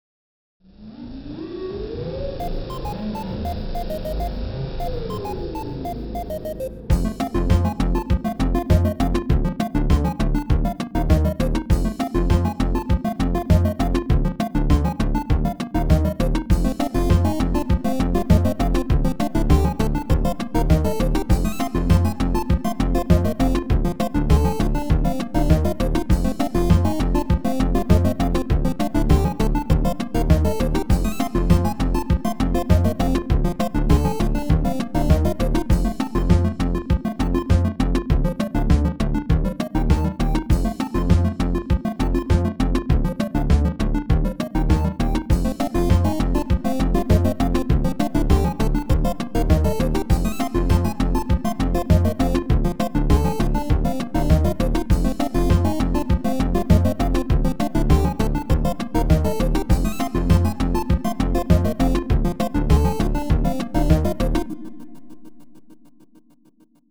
If you have a thing for 8bit, you might